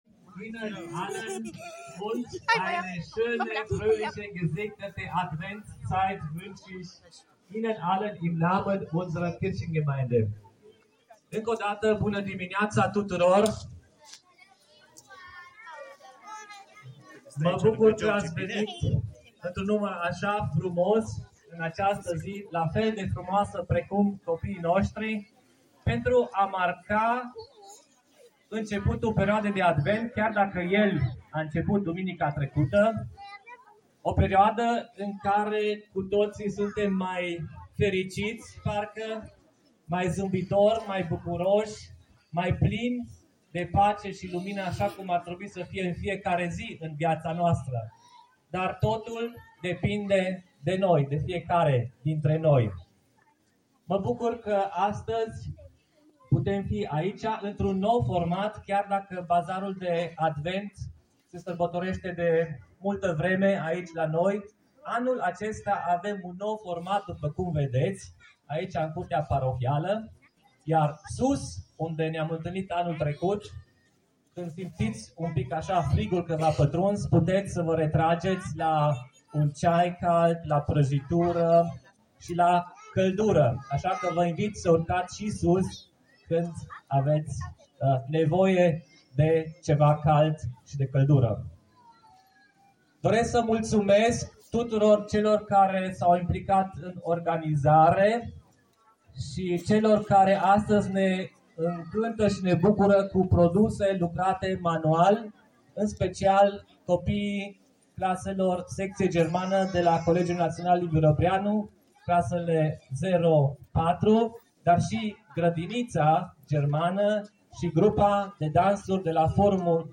Curtea Parohiei Evanghelice CA din Bistrița s-a dovedit neîncăpătoare pentru copiii care învață în secția germană a Colegiului Național LIVIU REBREANU (clasele 0-4), Grădinița Germană dar și Grupa de dans a Forumului Democrat German Bistrița.